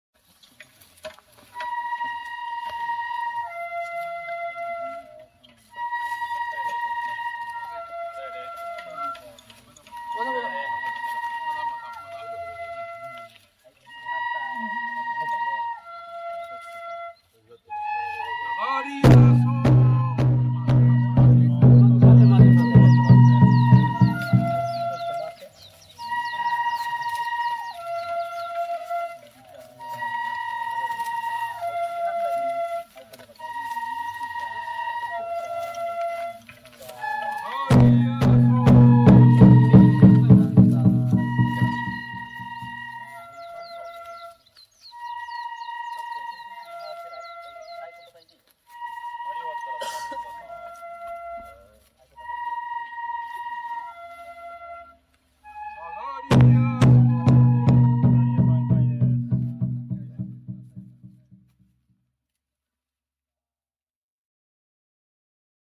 ここで執り行われる神事は、八撥神事（やさばきしんじ）と呼ばれる稚児舞楽で、神さまを稚児に憑依させる儀式だという。
その時、太鼓を鳴らしながら、氏子総代が掛け声をかける。
「さがりやそー」で左に3回。
「あがりやそー」で右に3回。